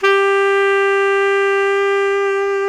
SAX TENORB0U.wav